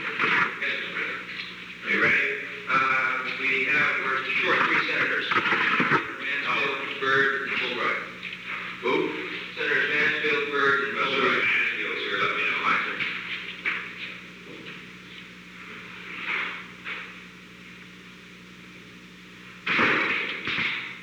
Secret White House Tapes
Conversation No. 908-6
Location: Oval Office
The President met with an unknown man.